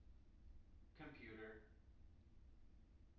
wake-word
tng-computer-155.wav